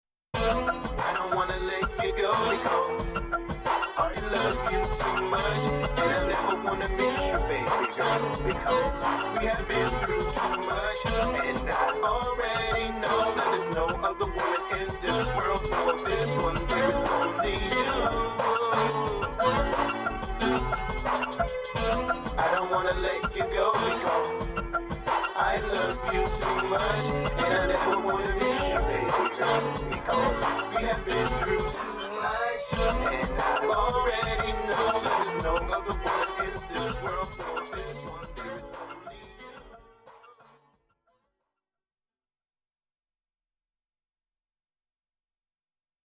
dance/electronic
(full length instrumental)